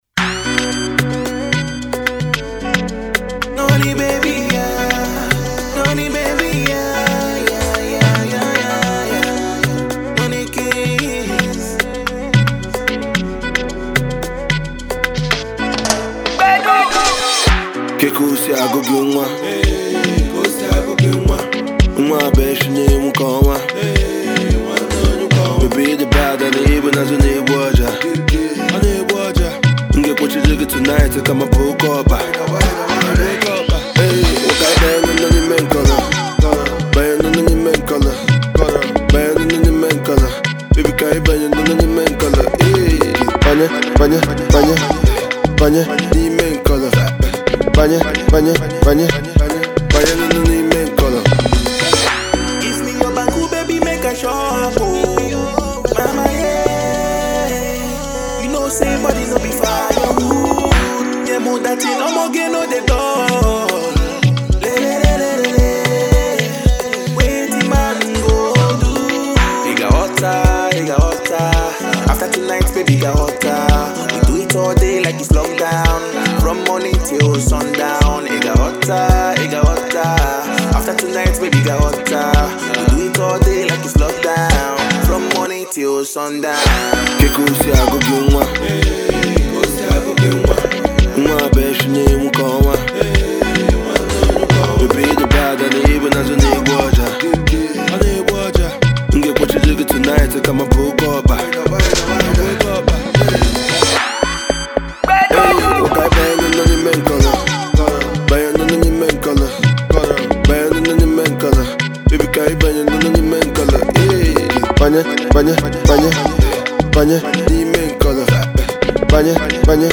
Afrobeat
street hit song